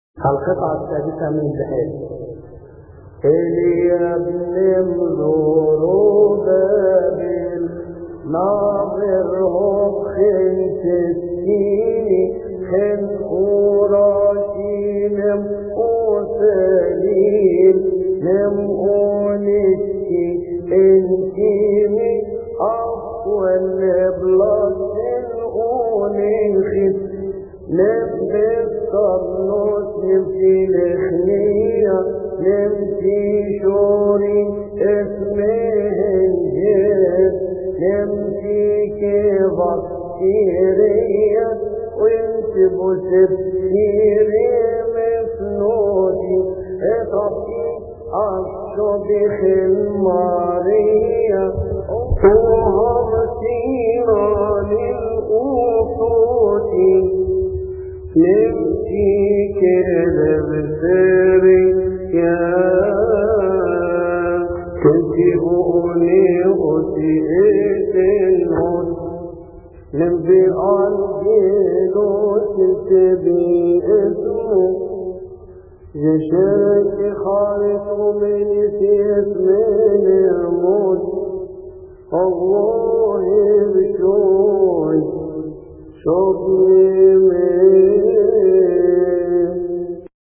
التفسير السادس من البحيري (1) لثيؤطوكية السبت يصلي في تسبحة عشية أحاد شهر كيهك